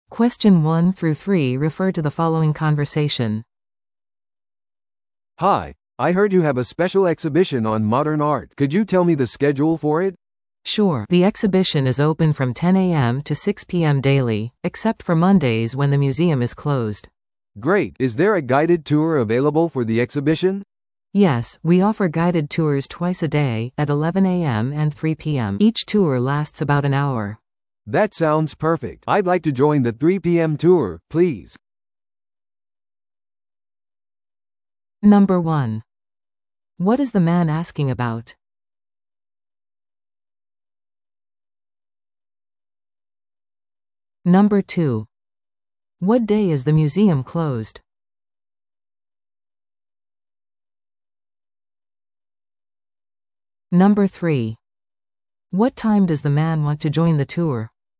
PART3は二人以上の英語会話が流れ、それを聞き取り問題用紙に書かれている設問に回答する形式のリスニング問題です。
Museum visit inquiry